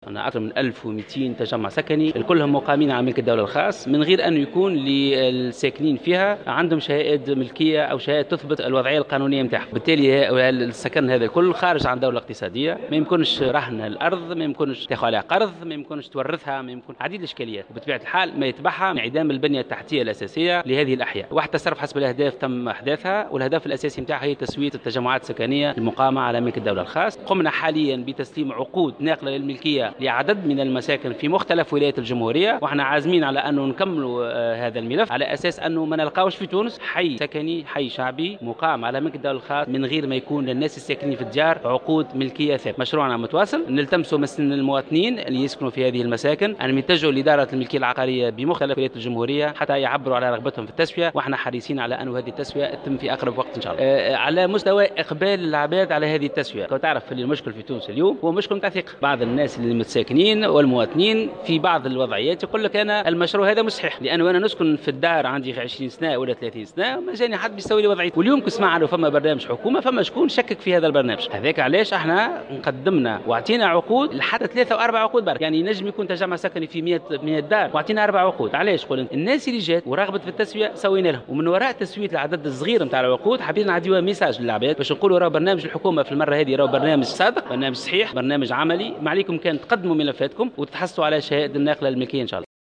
أكد وزير أملاك الدولة والشؤون العقارية الهادي الماكني اليوم في تصريح للجوهرة "اف ام" عقب جلسة استماع له عقدت بمجلس نواب الشعب أن أكثر من1200 تجمع سكني مقامة على ملك الدولة الخاص دون تحوز ساكنيها على شهادة ملكية او شهائد تثبت الوضعية القانونية.